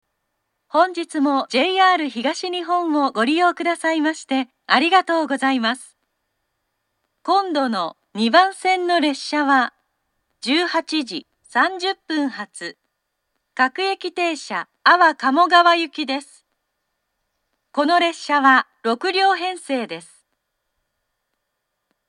２番線下り次発放送 18:30発各駅停車安房鴨川行（６両）の放送です。
katsuura-2bannsenn-kudari-jihatsu3.mp3